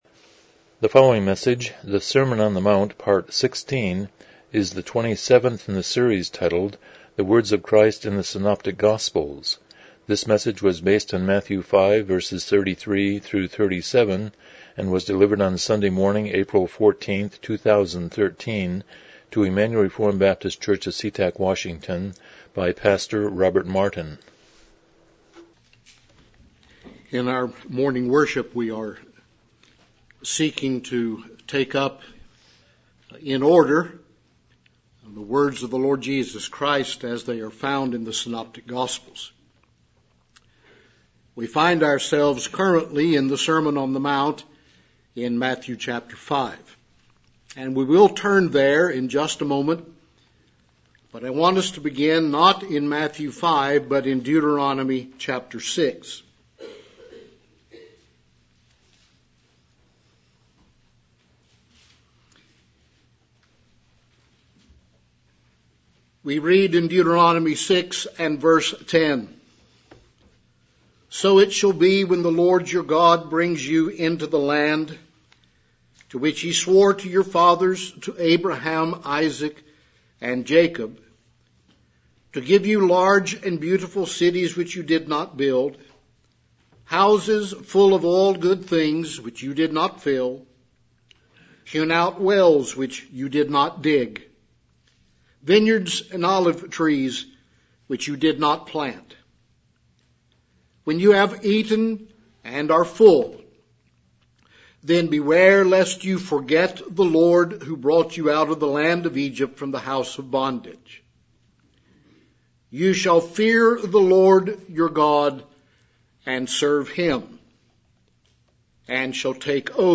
Passage: Matthew 5:33-37 Service Type: Morning Worship